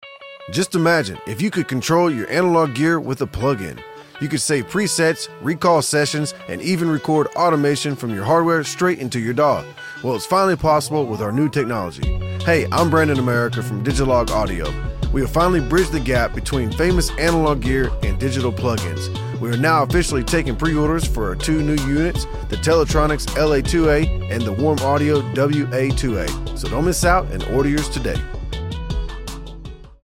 Real analog Mp3 Sound Effect First time ever!! Real analog sound with digital control!